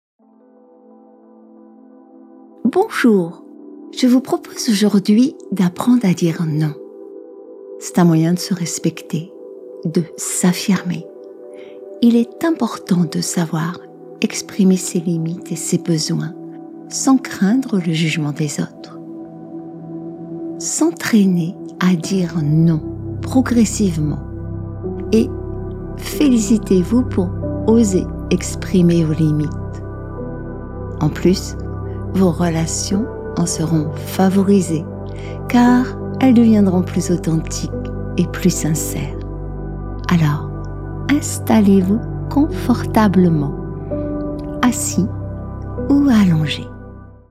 Méditation guidée – Affirmation de soi – Savoir dire “Non”